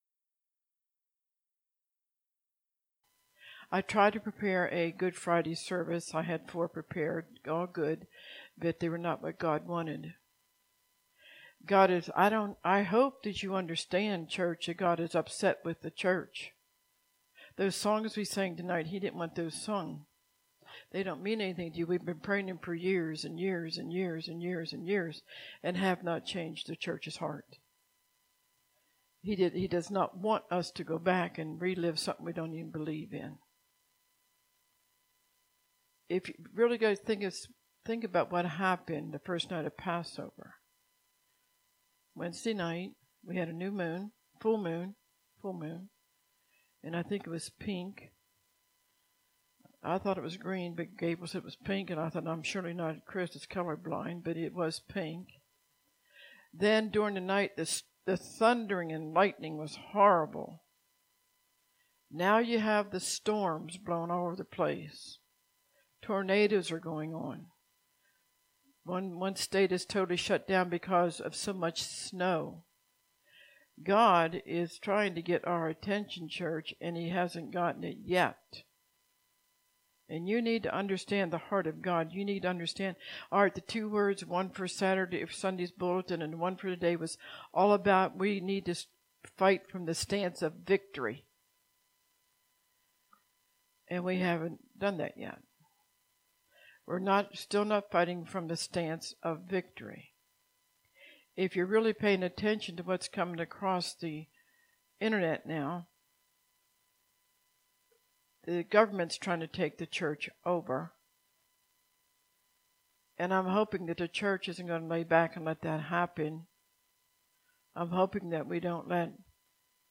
In this prophetic sermon, God lets us know what lies ahead for those who trust in Him.